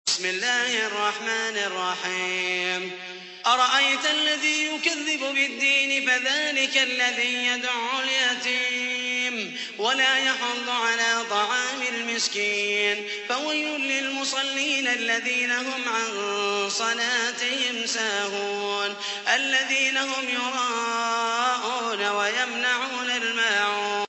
تحميل : 107. سورة الماعون / القارئ محمد المحيسني / القرآن الكريم / موقع يا حسين